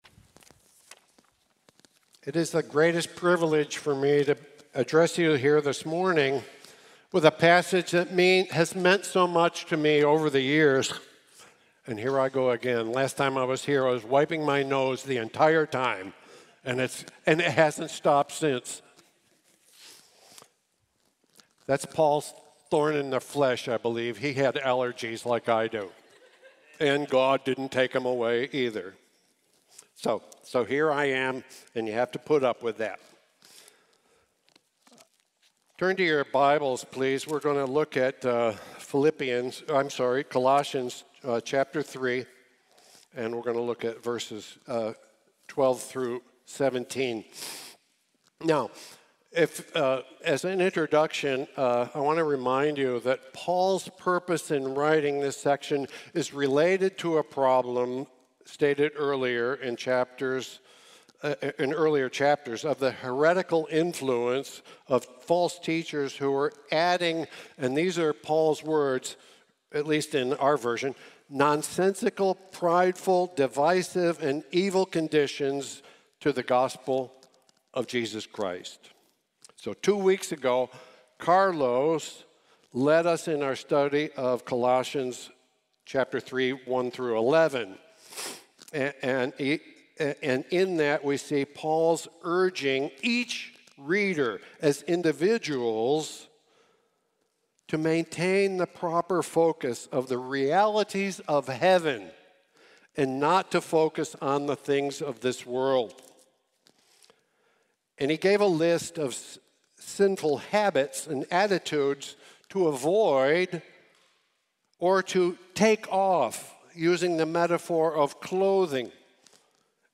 El traje nuevo del emperador | Sermón | Iglesia Bíblica de la Gracia